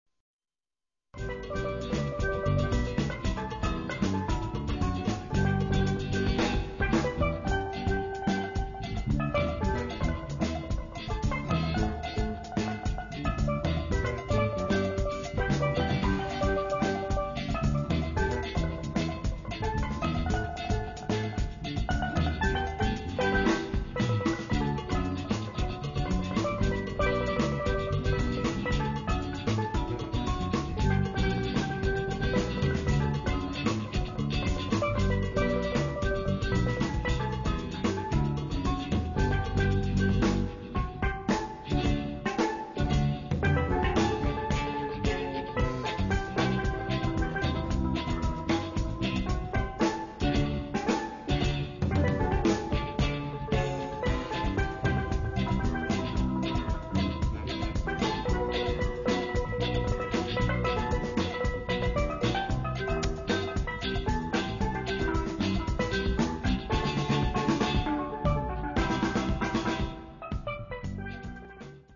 Saxes, vocal, flute, percussion, pan
Guitars
Bass
Fusing funk, world beat, jazz and joy